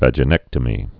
(văjə-nĕktə-mē)